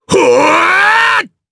Phillop-Vox_Attack4_jp.wav